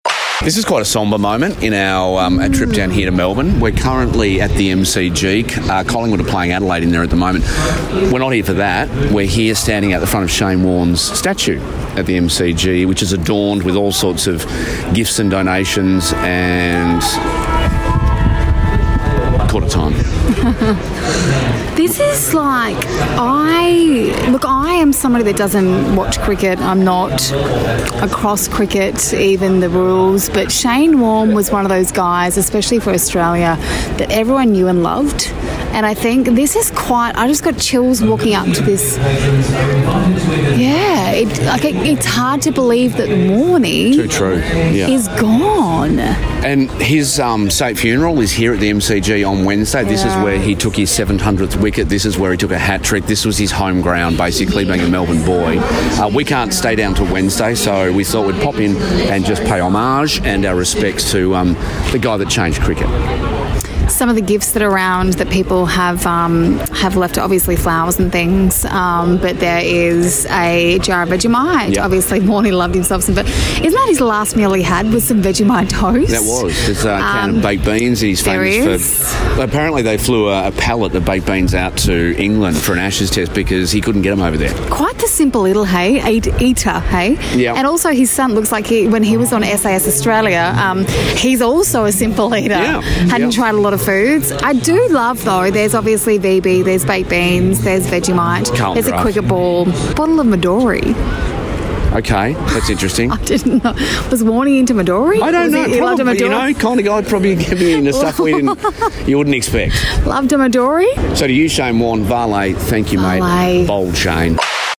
While we were in Melbourne for Chip Fest aka Maximum Chips, we popped over to the MCG to take in the Shane Warne monument.